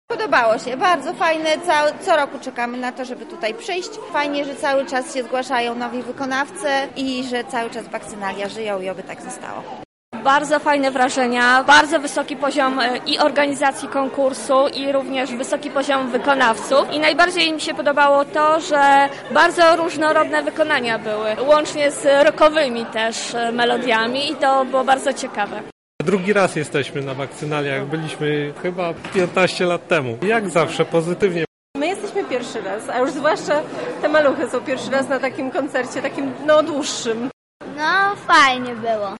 Nasz reporter rozmawiał z widzami o ich wrażeniach po koncercie: